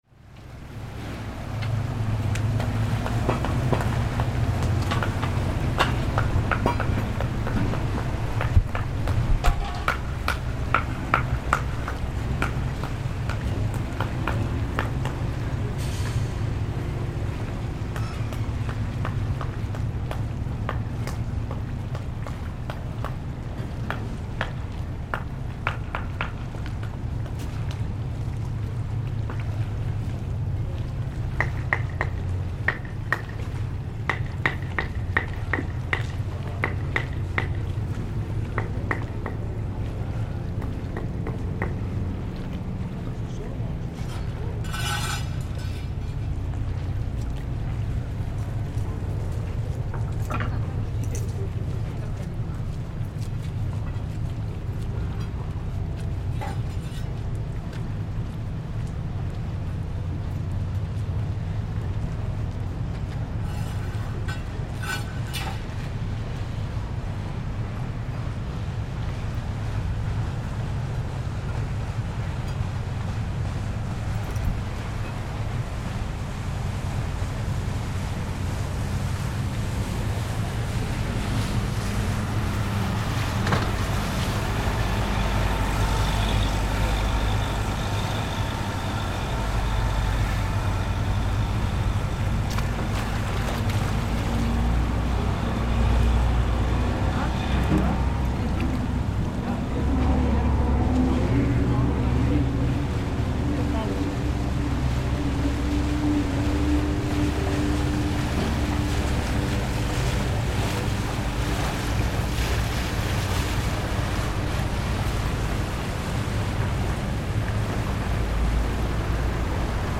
Pavement works in Cannaregio, Venice
Walk around Venice on a regular weekday and you'll hear the sounds of construction and repair, as you would in any other city.
Here in Cannaregio, at the northern end of Venice's main island, workmen are maintaining the canalside pavements, while boat after boat comes in and out of Venice from the lagoon to the north.